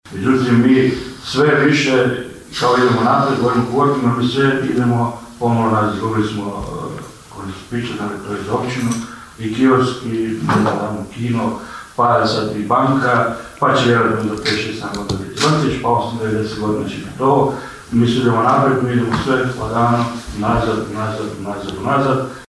Na sjednici Općinskog vijeća Kršana raspravljalo se o najavi zatvaranja poslovnice Erste banke u Potpićnu.
Na ovotjednoj sjednici Općinskog vijeća Kršana vijećnik s liste Romana Carića Esad Huskić govorio je o najavi zatvaranja poslovnice Erste banke u Potpićnu.